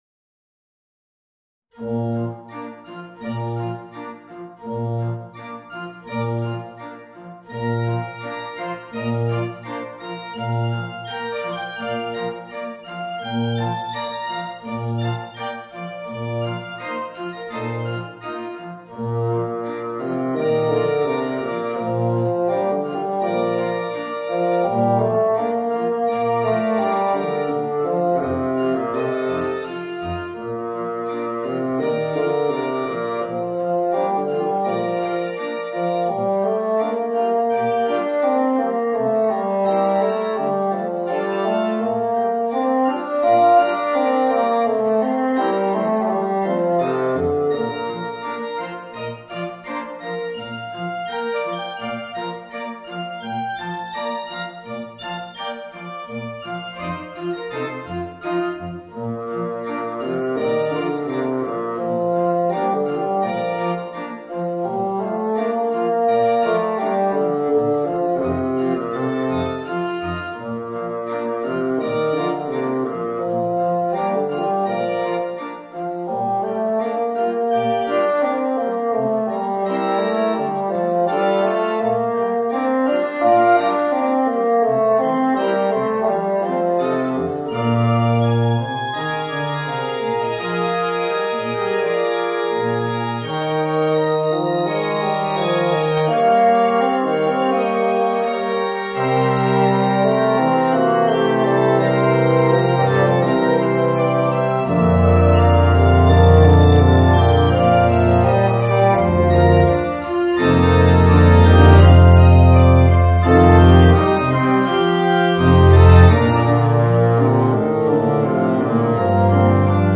Voicing: Bassoon and Piano